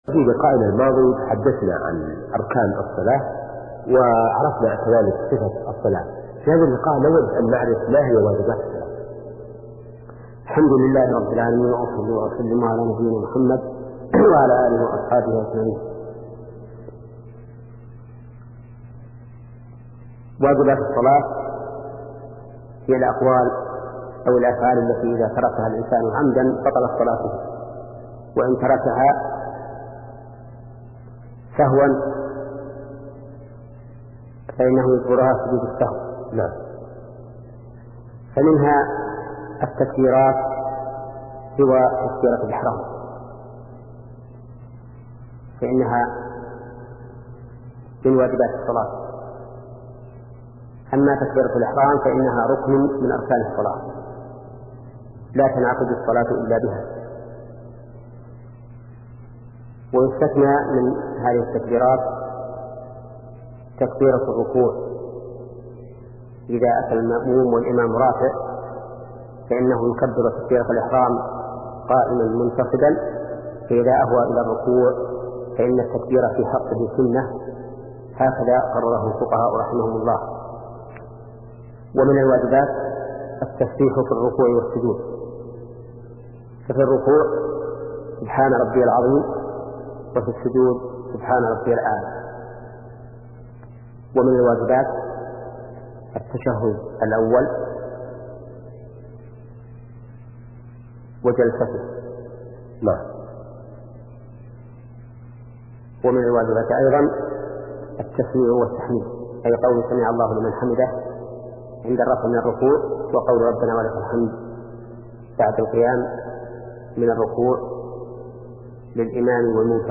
شبكة المعرفة الإسلامية | الدروس | فقه العبادات (29) |محمد بن صالح العثيمين